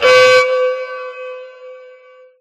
Siren.ogg